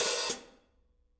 R_B Hi-Hat 06 - Close.wav